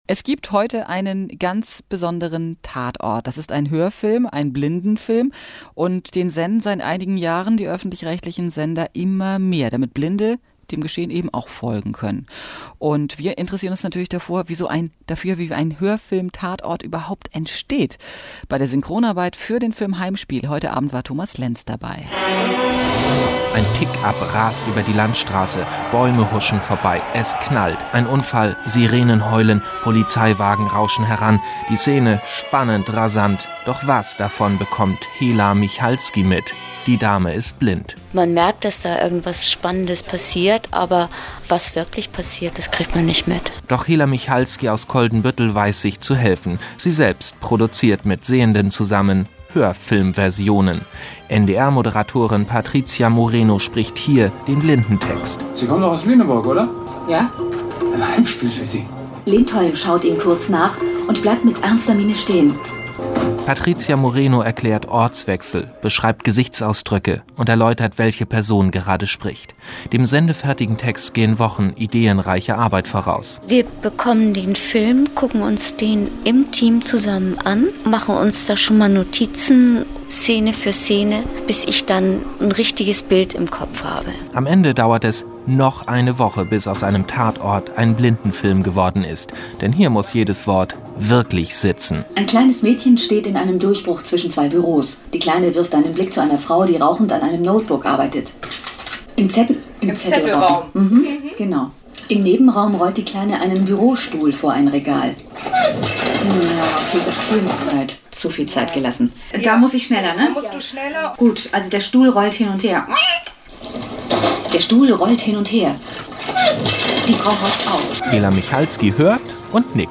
Anhören!Ausschnitt aus NDR1 Welle Nord am Vormittag vom 3.3.2006 um 9:20 Uhr;